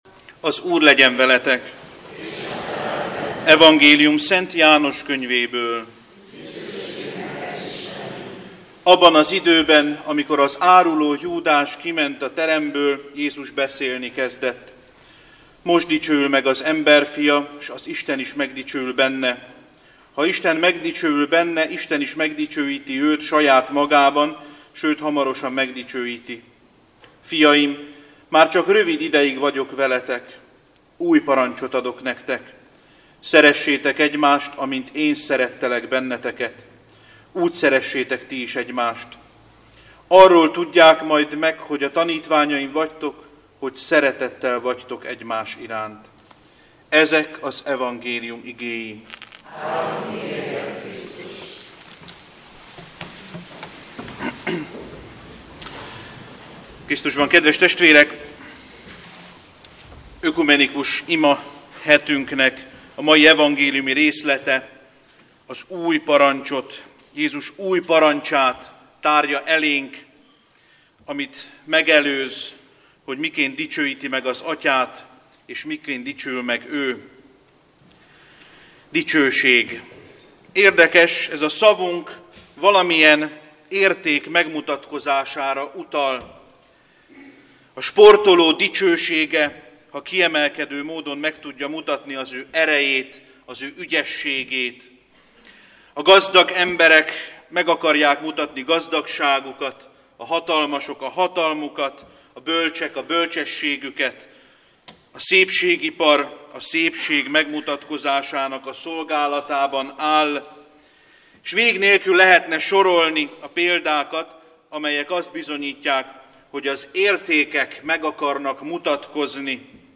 Ökumenikus imahét.